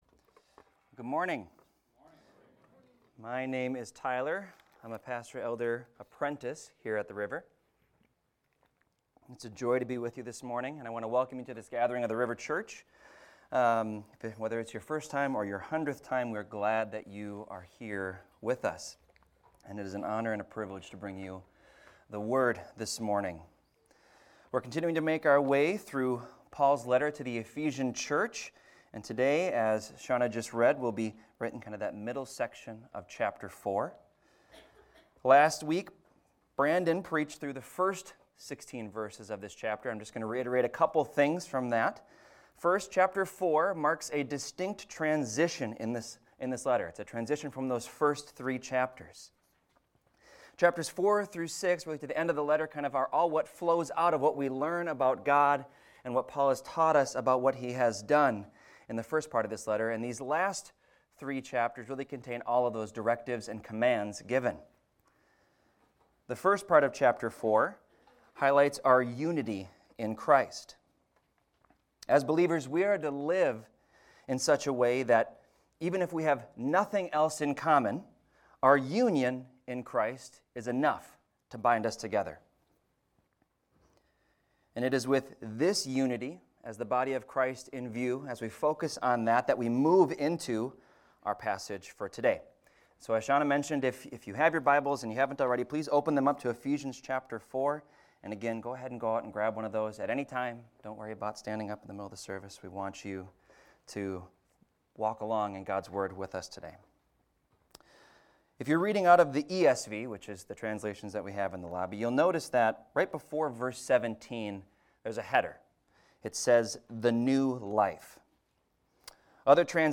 This is a recording of a sermon titled, "Put On The New Self."